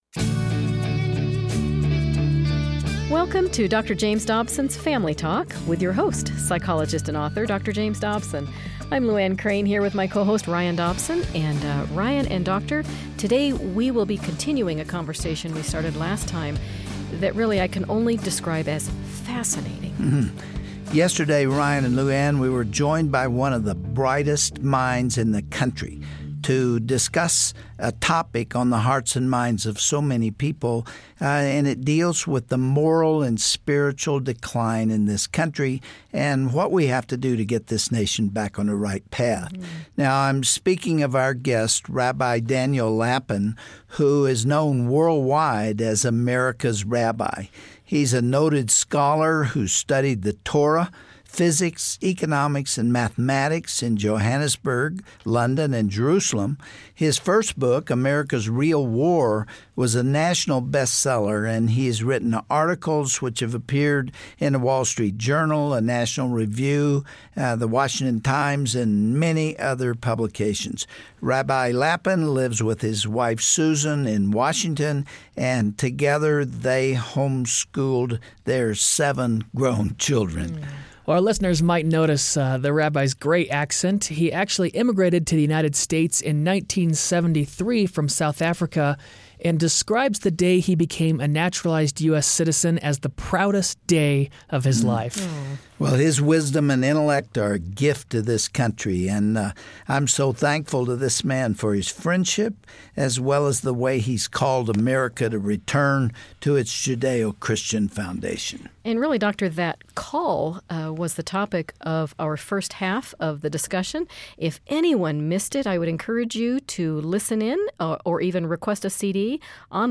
Today, you will hear how our nation got to this point, and what it's going to take to right the ship. It's a discussion no American should miss.